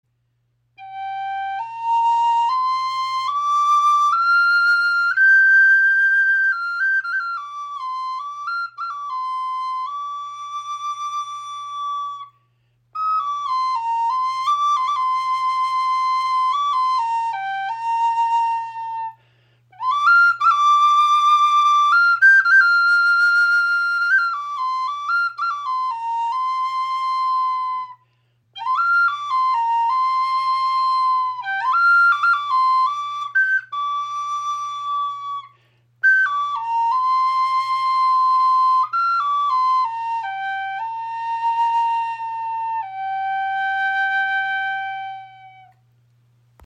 Pocket Spirit Flöte in G-Moll | aromatische Zeder | für kleine Hände
• Icon Warmer, klarer Klang – ideal für unterwegs und Anfänger
Gefertigt aus einem einzigen Stück aromatischer Zeder, liegt sie mit ihren 20 cm leicht in der Hand – und passt mühelos in jede Tasche. Ihr warmer, klarer Klang überrascht alle, die sie zum ersten Mal hören.
High Spirits Flöten sind Native American Style Flutes.